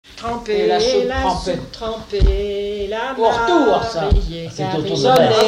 Chanté en arrivant à la messe de mariage
Pièce musicale inédite